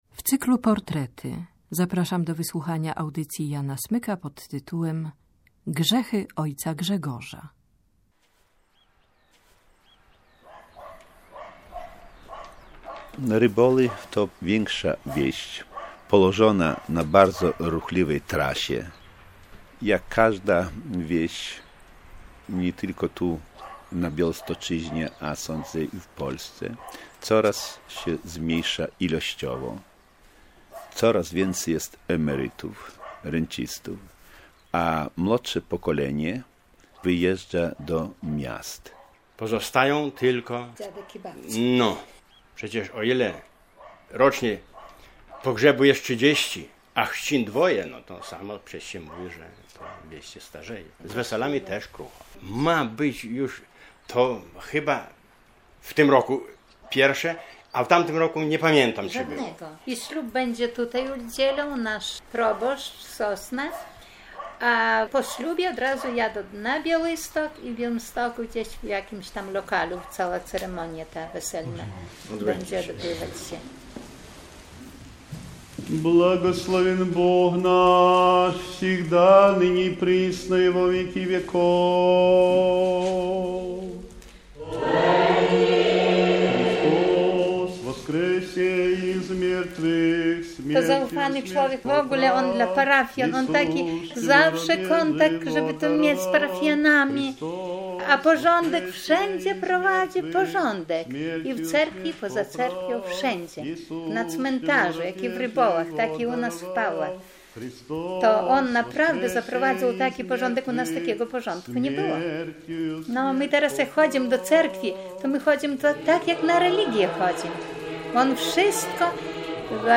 Miejsce nagrania: Ryboły, Pawły, Producent: Polskie Radio Białystok.